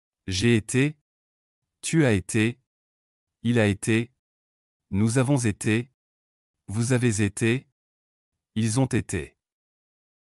Passé composé
etre-passe-compose.mp3